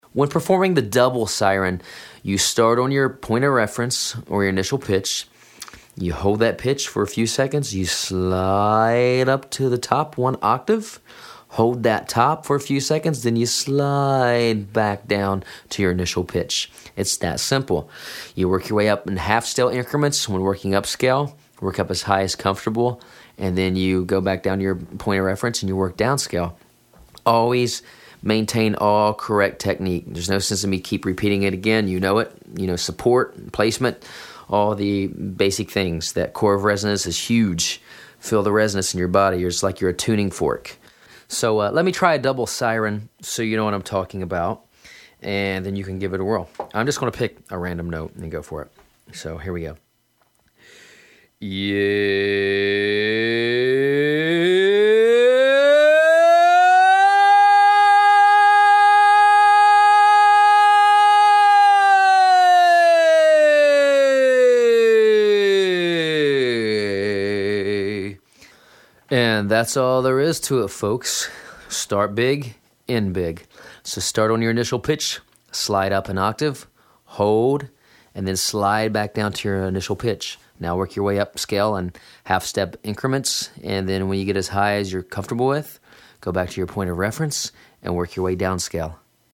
تمرینات Siren
سعی کنید تک‌به‌تک نت‌ها را با آوایی مانند “Yay” اجرا کنید و در هر مرحله، یک اکتاو بالا بروید و دوباره همان اکتاو را پائین بیائید.
همان‌طور که در بخش‌های قبل گفته شد، هدف تمرینات Siren ، اجرای یک‌نواخت و ملایم نت‌ها اکتاوهای مختلف است.
41_Double_Siren_Example.mp3